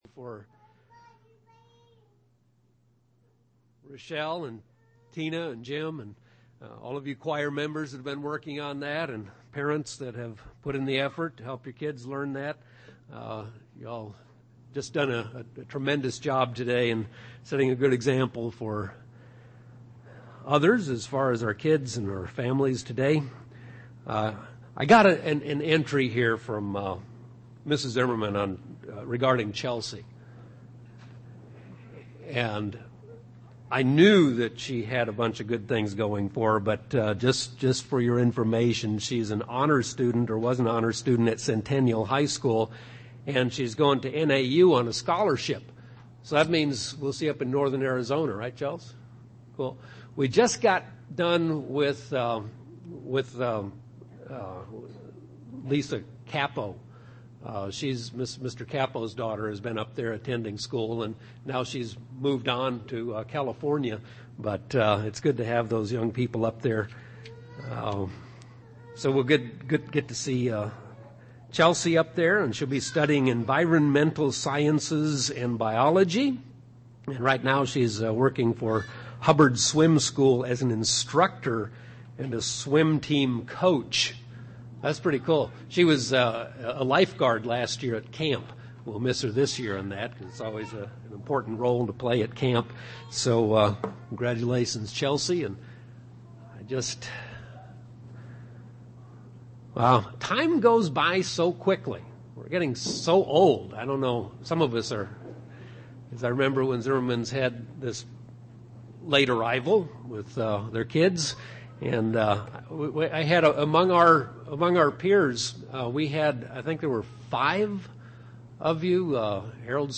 A Father’s Day message – Everyone is not a father, not all have a good example to follow. I want to talk about what it is that father’s want for their children in an ideal situation.
UCG Sermon Studying the bible?